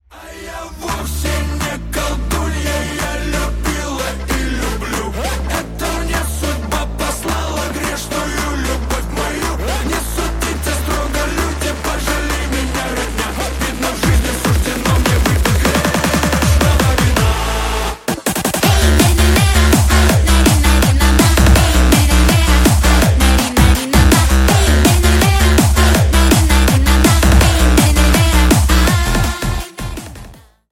• Качество: 320 kbps, Stereo
Поп Музыка
кавер